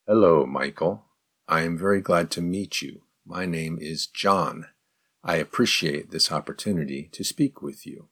01_advanced_response_slow.mp3